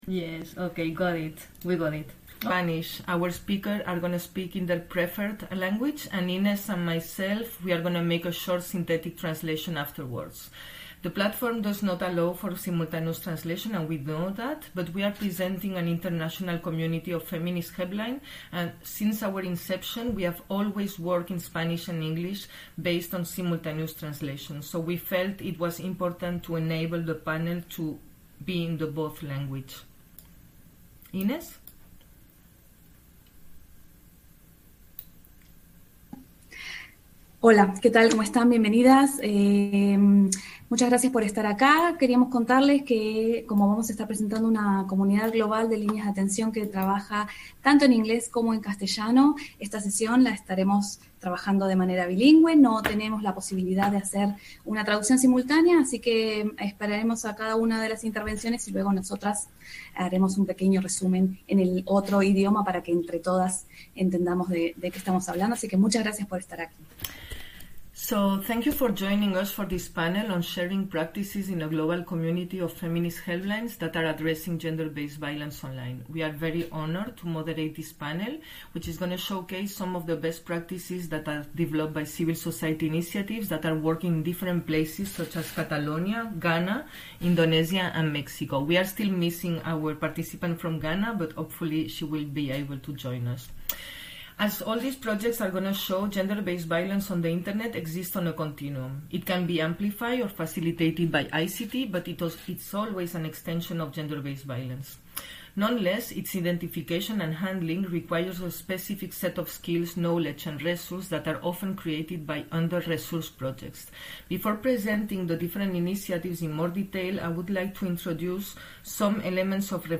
Las líneas de atención feministas se reunieron en RightsCon Costa Rica para compartir sus proyectos.